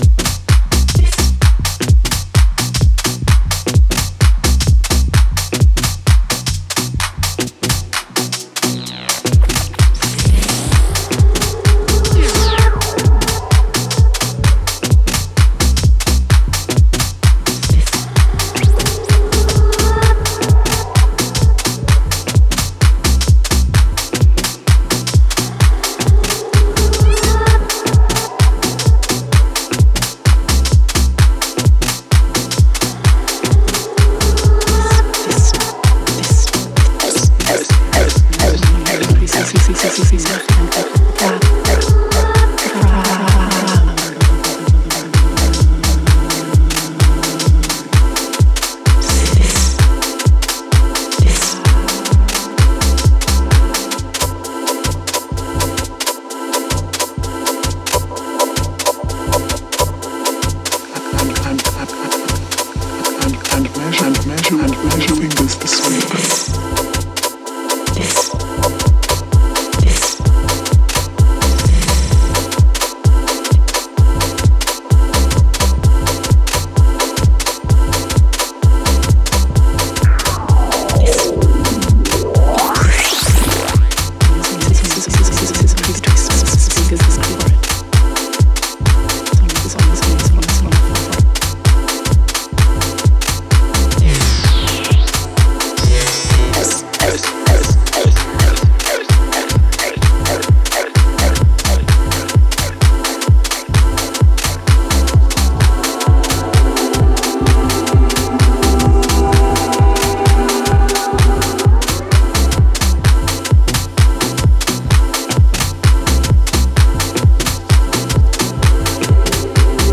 depth, warmth, a timeless dancefloor poetry.